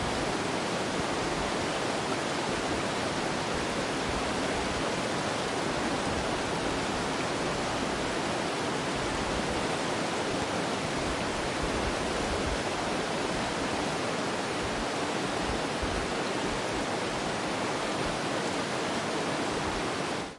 Roomtones / Ambience » RIVER RAPIDS AMBIENCE 01
描述：A river closely recorded with a Tascam DR40
标签： rapids river ambience water
声道立体声